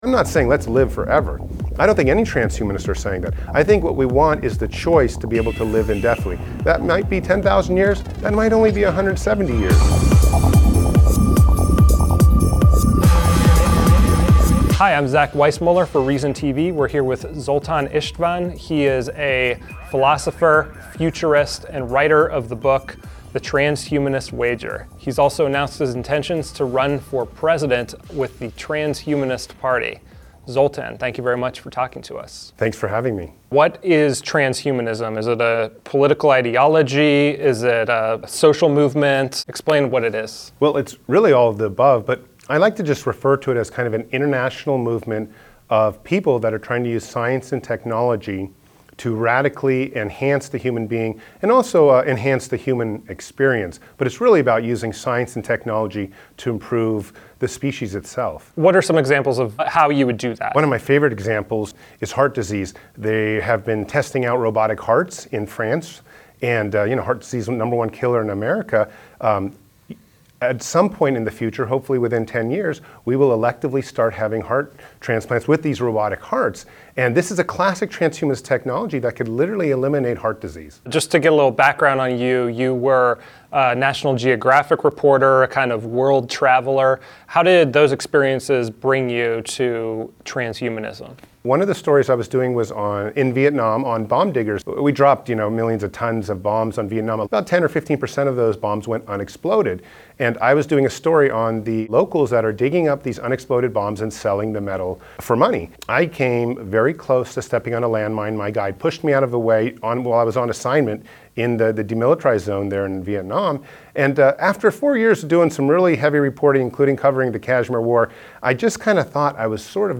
Scroll down for downloadable versions of this interview, and subscribe to Reason TV's YouTube channel for daily content like this.